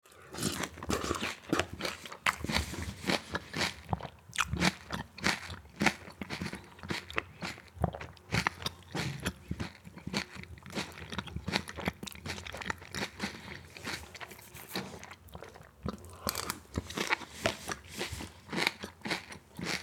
Download Free Eating Sound Effects | Gfx Sounds
Dinner-or-lunch-eating-food-with-fork-and-knife-2.mp3